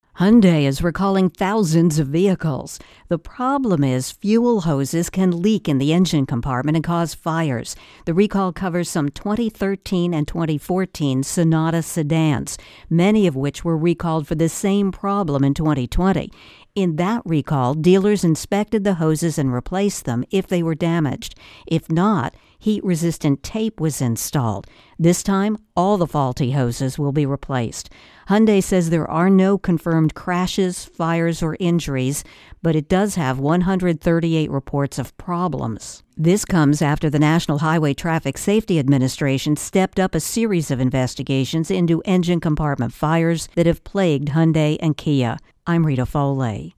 Hyundai Recall Intro+Voicer